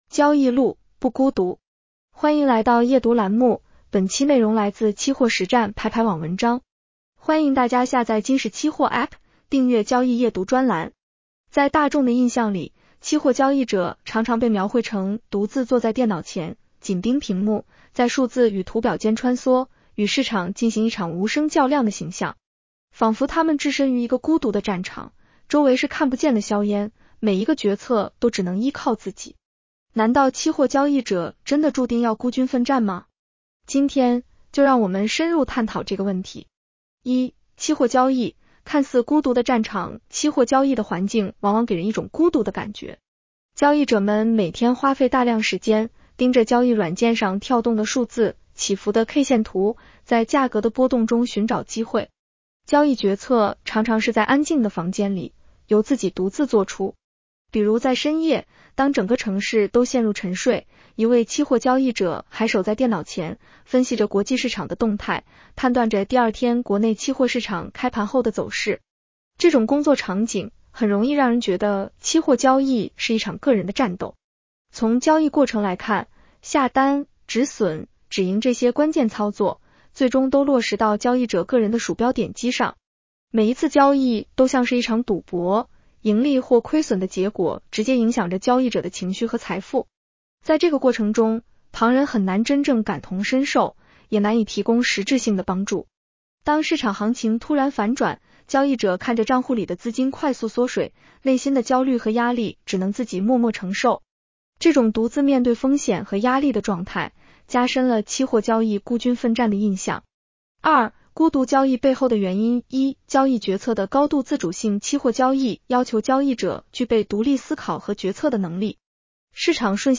女声普通话版 下载mp3 在大众的印象里，期货交易者常常被描绘成独自坐在电脑前，紧盯屏幕，在数字与图表间穿梭，与市场进行一场无声较量的形象。